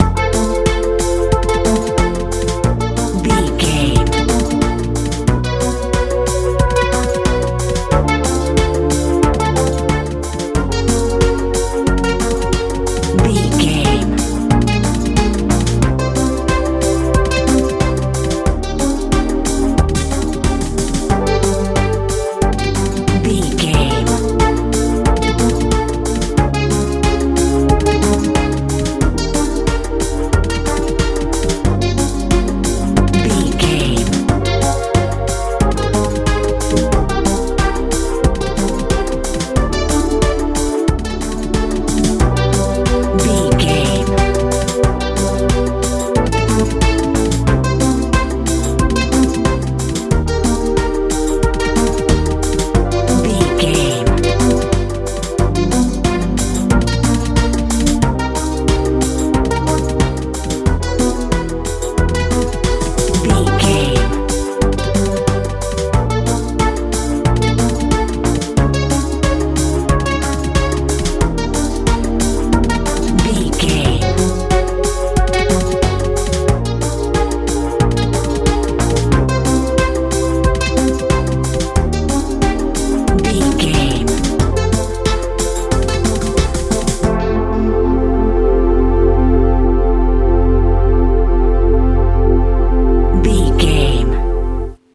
modern dance feel
Ionian/Major
strange
hypnotic
synthesiser
bass guitar
drums
80s
90s
suspense